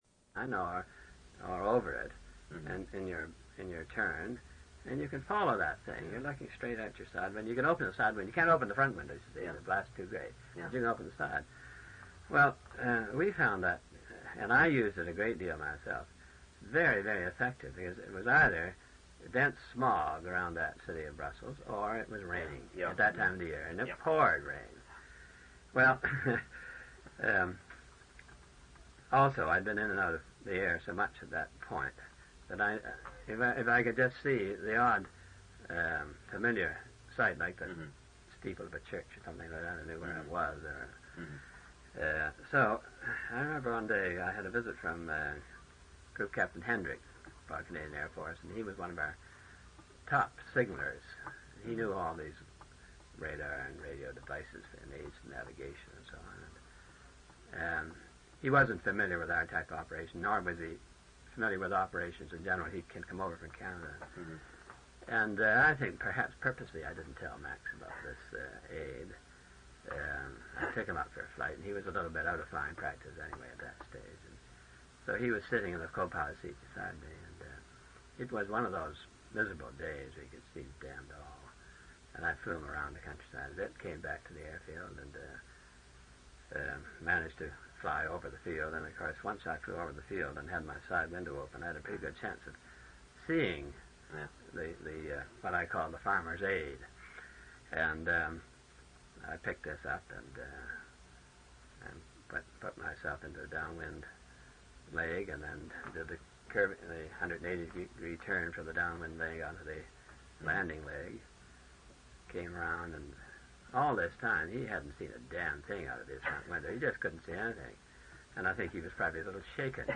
An interview/narrative of Clarence R. Dunlap's experiences during World War II. Air Marshal Dunlap, C.B.E., served with the Royal Canadian Air Force.